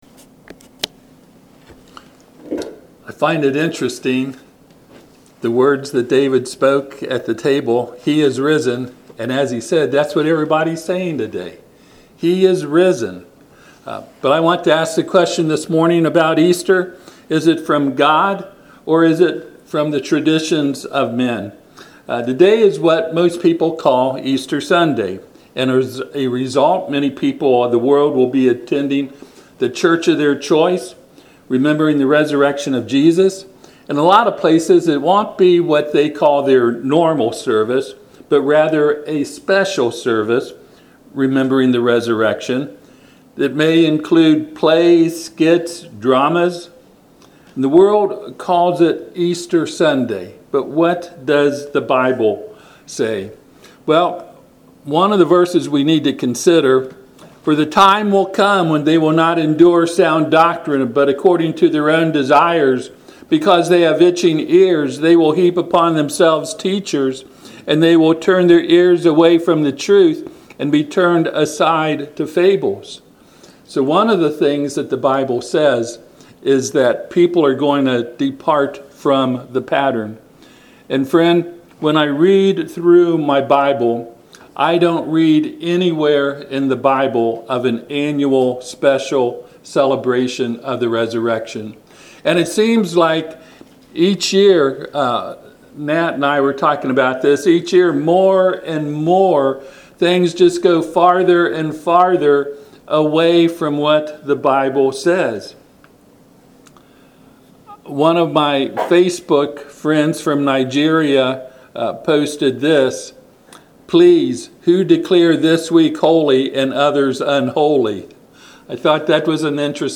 Passage: Acts 12:4 Service Type: Sunday AM